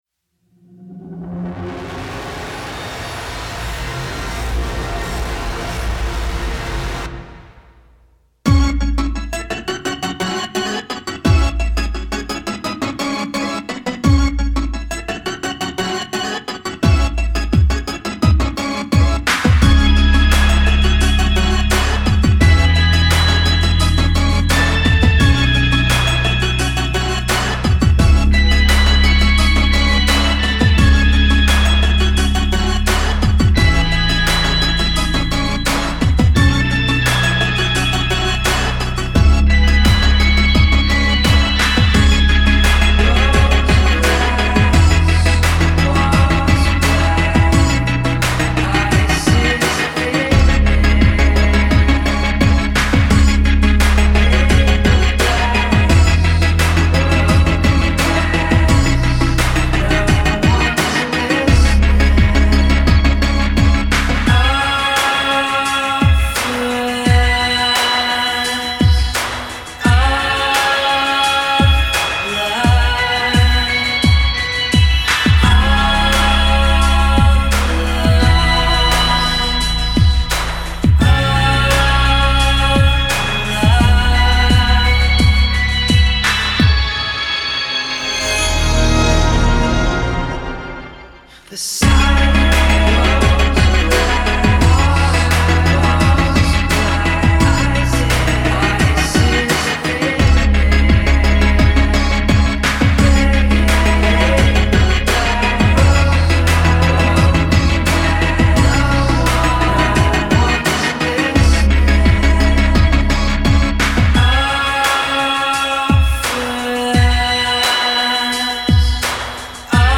home studio
dirge-slow and beaty